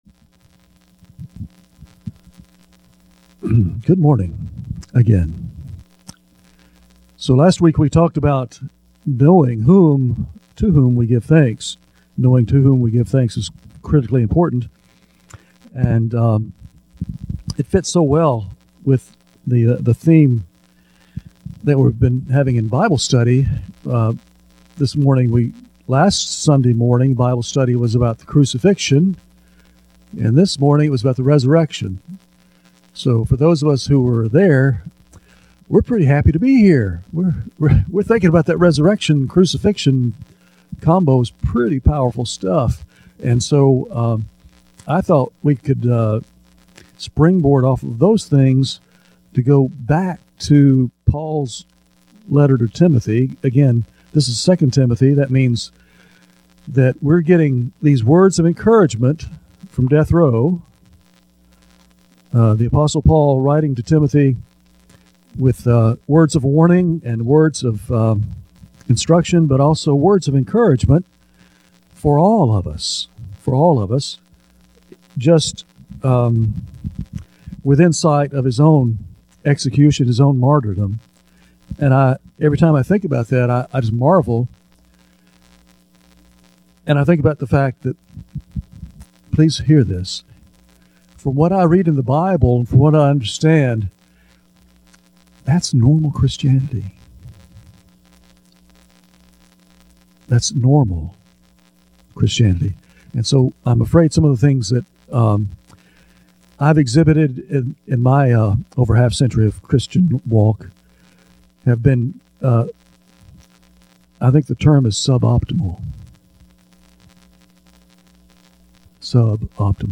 First Baptist Church of Hardeeville / Sermons from the Word of God